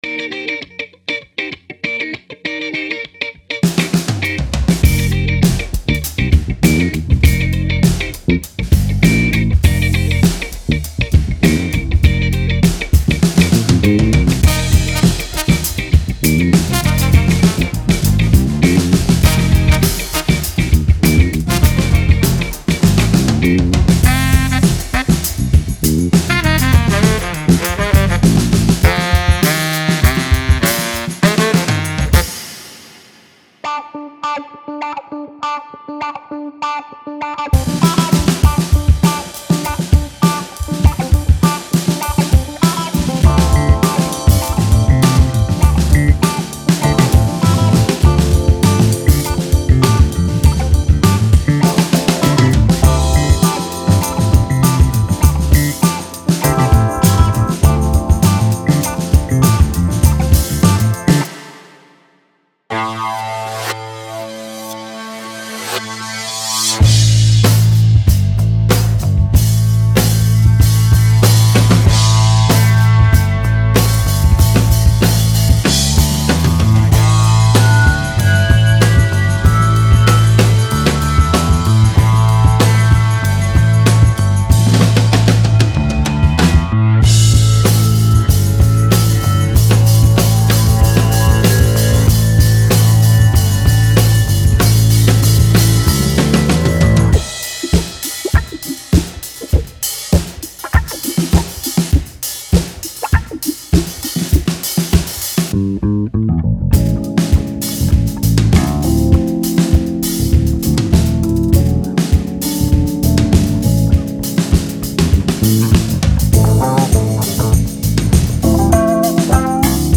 Genre:Rock
テンポは85 BPMから170 BPMまでをカバーしており、ミッドテンポの安定したグルーヴから高速で勢いのあるビートまで、ロックやインディ系のプロダクションに即戦力となる素材を収録しています。
レコーディングはイギリス・サセックスにある名門Ford Lane Studiosの広いライブルームで行われ、空間的な広がりと存在感あるドラムサウンドを実現しています。
パック内には170以上のライブドラムループが収録されており、2種類のスネアバリエーション、様々な演奏スタイルとリズムパターンを収録。
ファンキーなグルーヴ、アフロロックにインスパイアされたリズム、落ち着いたミドルテンポのプレイ、さらにはハードロック調のパワフルなビートまで、多彩な表現が可能です。
使用されたドラムセットはYamahaおよびDW製。
シンバルにはZildjian KとA Customを採用。
録音にはColes 4038リボンマイクを含むヴィンテージとモダンのマイクを組み合わせ、Neve 1073プリアンプにAmekおよびSSL GシリーズのEQを通して音を整えています。
ミキシングにはZuluパッシブテープコンプレッションが使用され、味わい深いサチュレーションと圧縮感を持ちながらも、あえて余白を残したサウンドに仕上げられており、ユーザーが自分のプロジェクトに合わせて追加の加工をしやすい仕様です。
38 Funk Rock Drum Loops
8 Ballad Rock Drum Loops